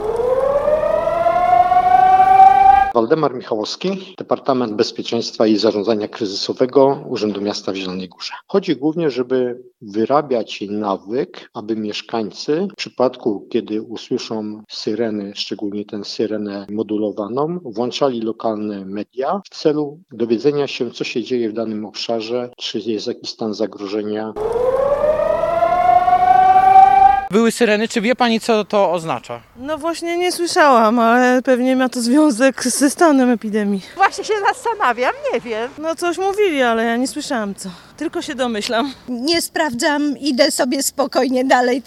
Dziś w Zielonej Górze zawyły syreny – odbył się test systemu ostrzegania ludności.
Równo o 12:00 można było usłyszeć komunikaty o próbie systemu ostrzegania ludności z 42 urządzeń znajdujących się w całym mieście. Następnie zabrzmiał modulowany sygnał syren.